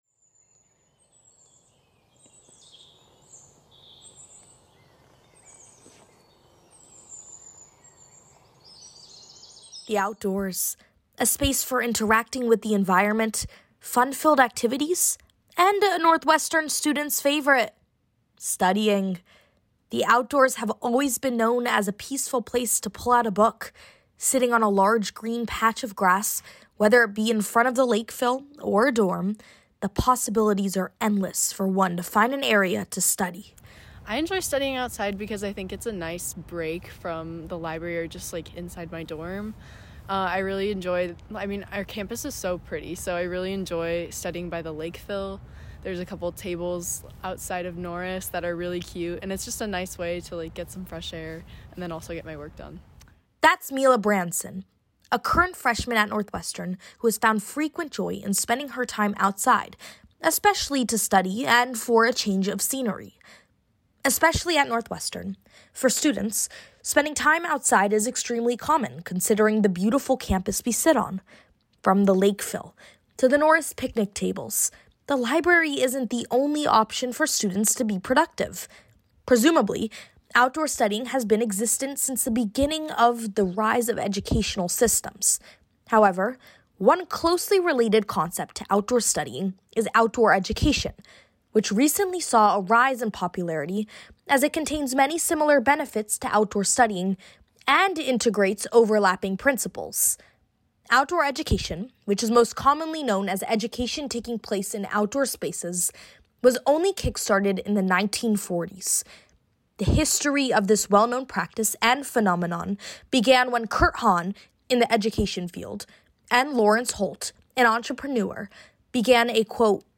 This story originally aired as part of our WNUR News Touches Grass Special Broadcast.